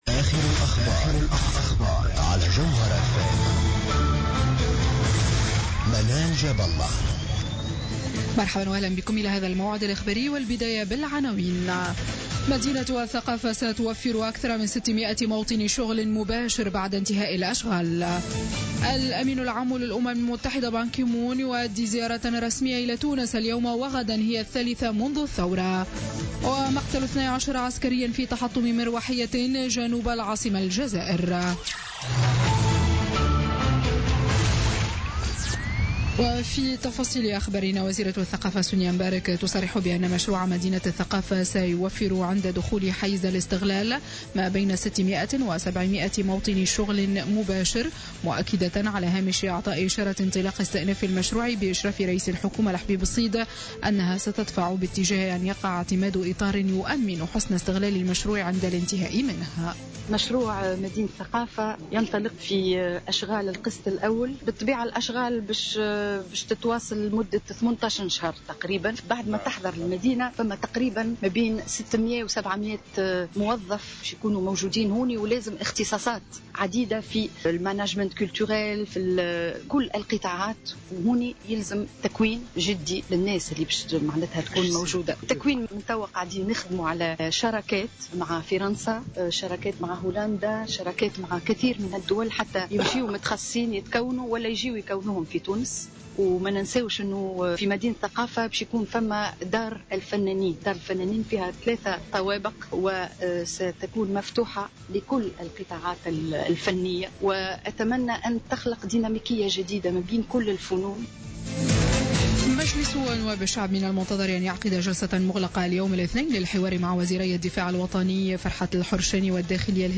نشرة أخبار منتصف الليل ليوم الاثنين 28 مارس 2016